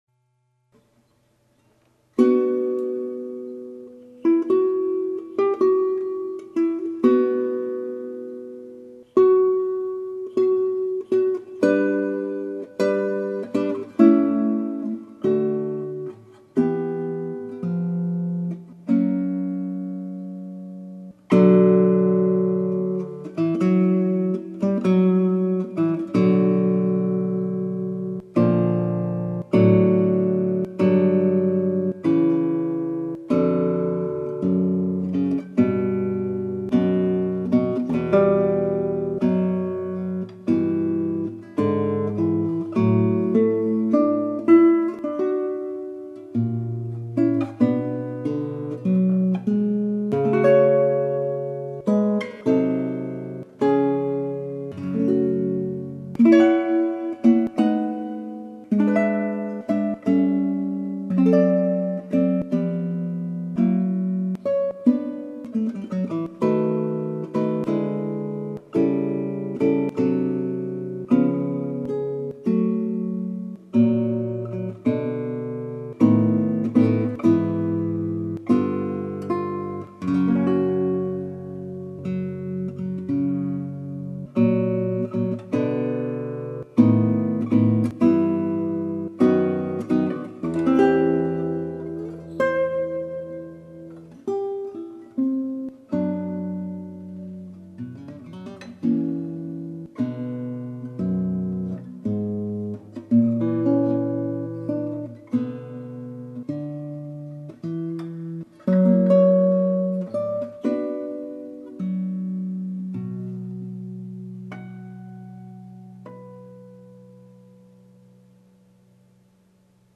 2. Andante reliogioso : chậm răi và cung kính